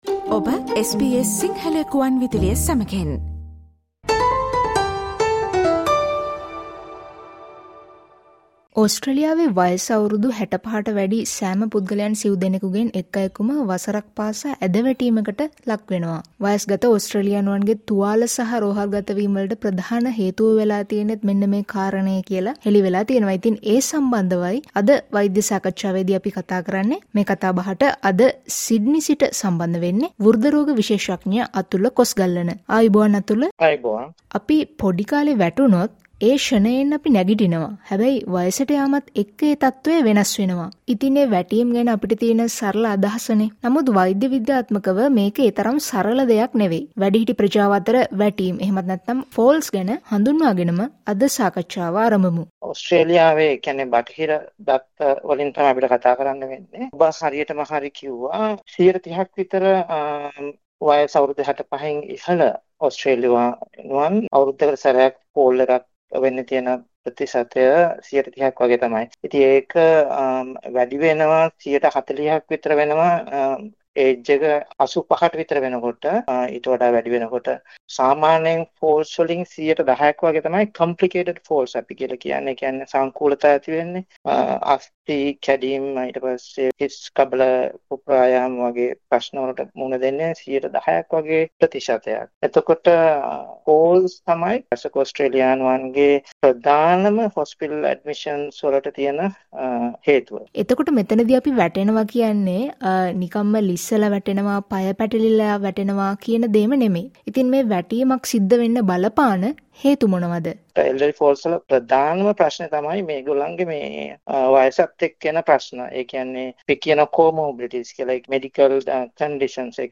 වැටීම්වලට හේතු සහ එහි සංකූලතා පිළිබඳව සිදු කළ වෛද්‍ය සාකච්ඡාවේ පළමු වැඩසටහනට ඔබත් සවන් දෙන්න.